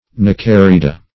Search Result for " neocarida" : The Collaborative International Dictionary of English v.0.48: Neocarida \Ne`o*car"i*da\, n. pl.
neocarida.mp3